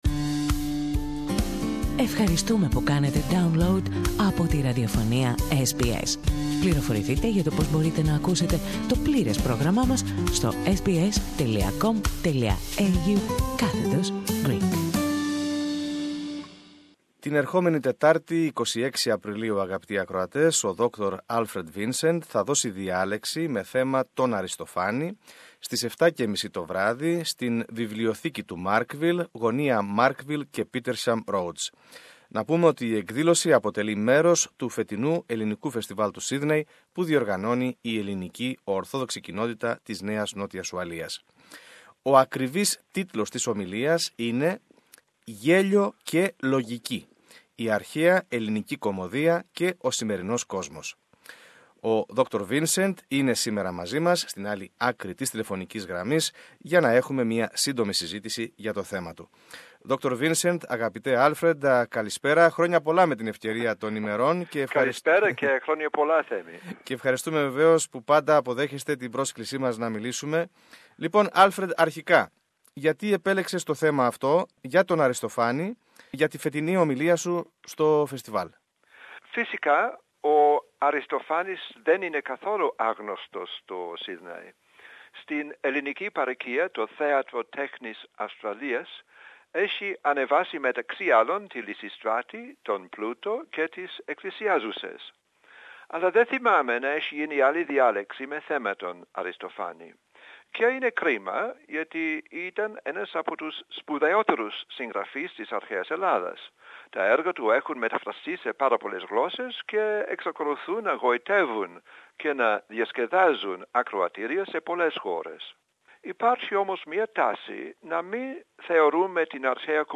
Περισσότερα στην συνέντευξη